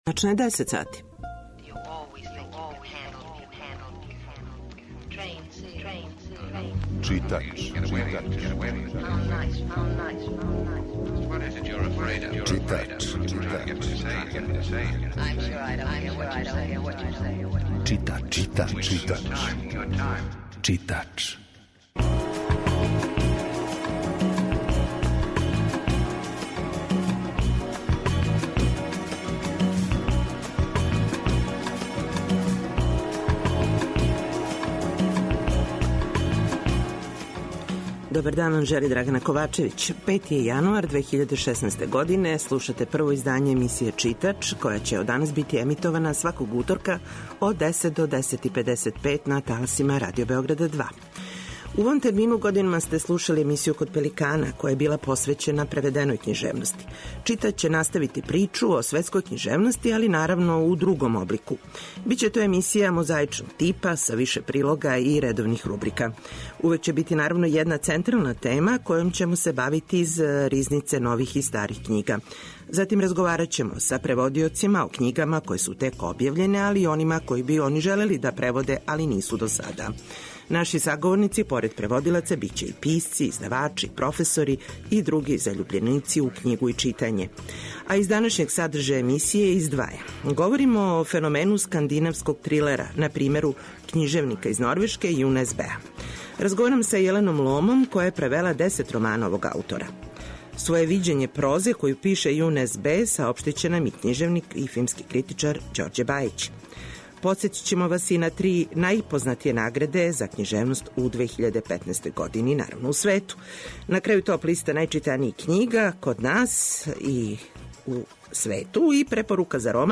У Новој 2016. години стартује и нова емисија на Радио Београду 2 - 'Читач' - која се емитује сваког уторка од 10 до 10.55. Емисија је колажног типа, али је њена основна концепција - прича о светској књижевности.